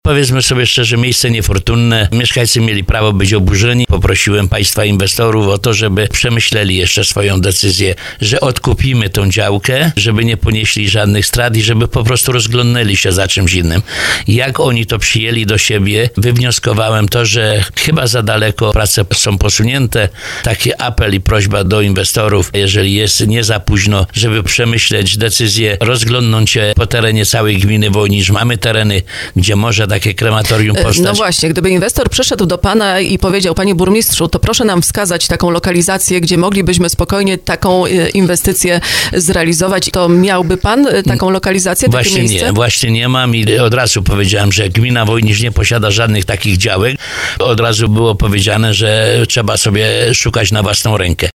Burmistrz Wojnicza, Tadeusz Bąk, ma nadzieję, że inwestor zrezygnuje z realizacji planów budowy krematorium na terenie Dębiny Zakrzowskiej. Jak podkreślił w porannej rozmowie Słowo za Słowo na antenie RDN Małopolska, samorząd zrobił w tej sprawie wszystko, co było w jego mocy.